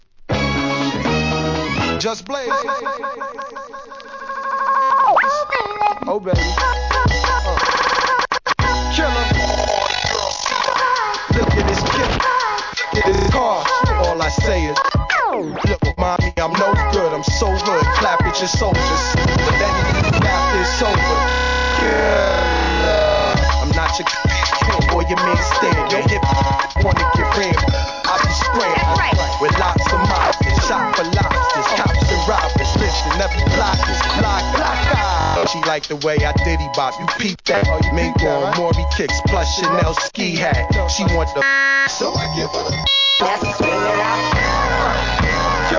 HIP HOP/R&B
やりすぎCUT UP物!!